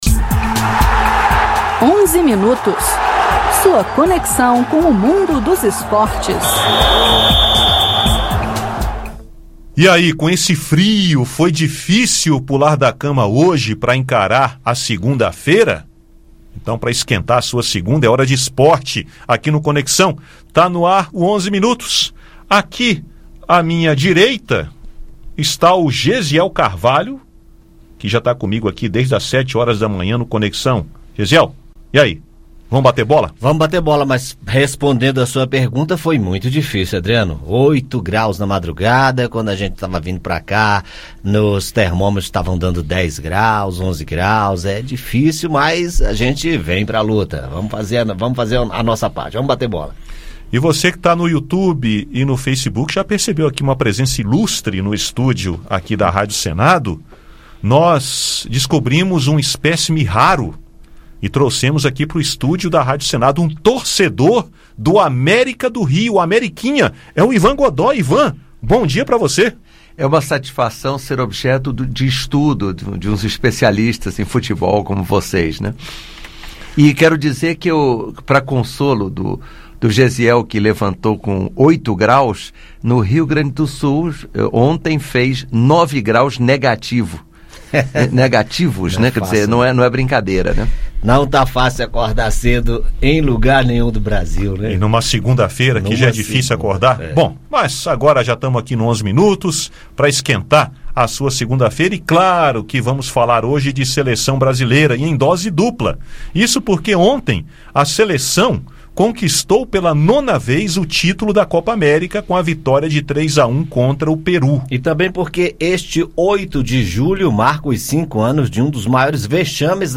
Esses são os assuntos dos comentaristas do Onze Minutos. Ouça o áudio com o bate papo.